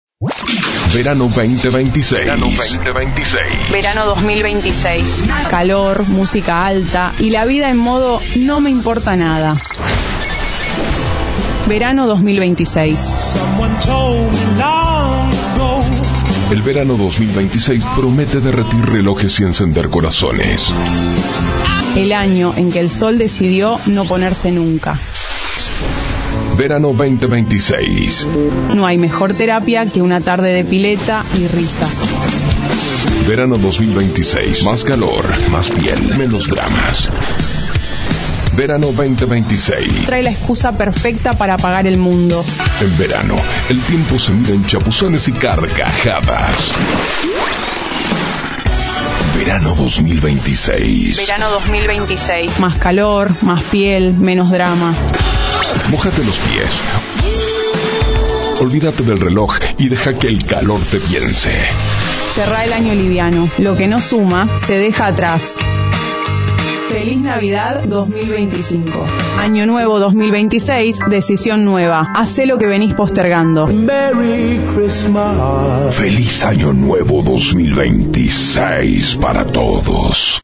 Artistica de Verano confeccionada a UNA VOZ Inconfundibles, única y con Personalidad.
Editados con Música y Efectos
Con voz en OFF
Producción confeccionada a 1 voz